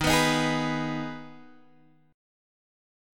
Ebsus4#5 chord